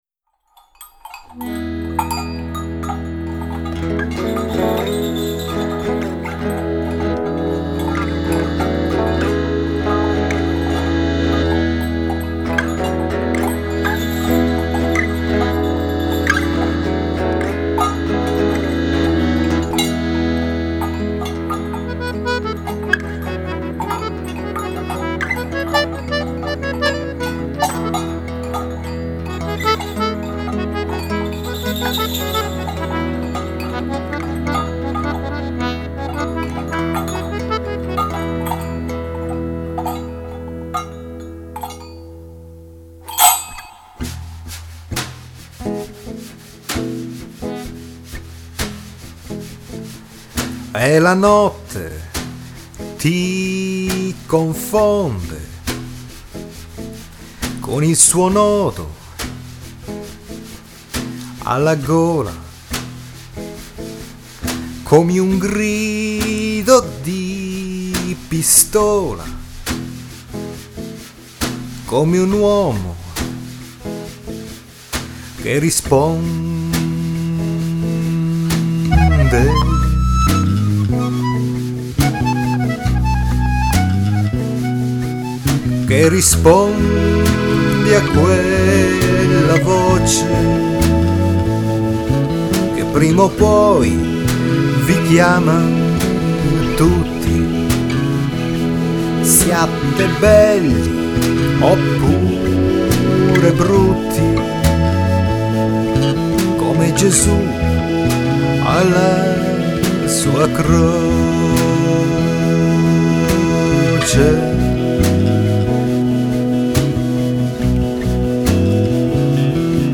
ai suoi ritmi lenti e dolenti.